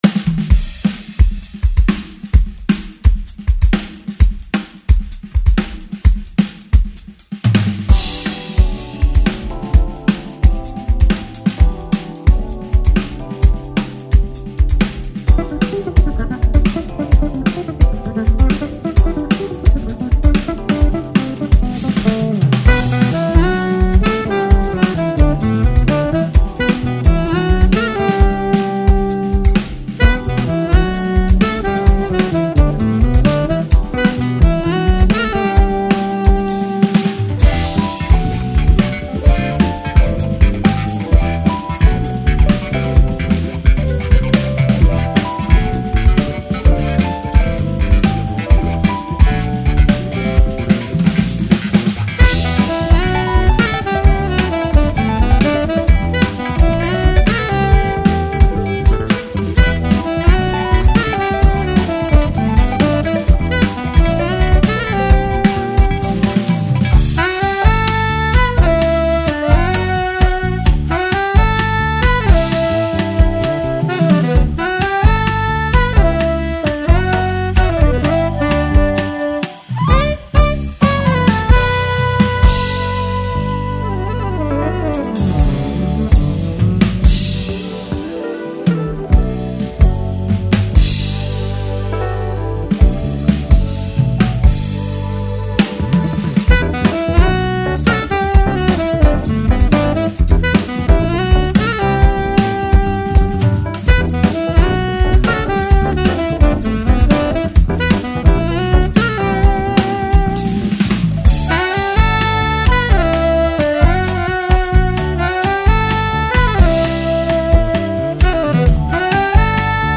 2908   05:57:00   Faixa: 4    Jazz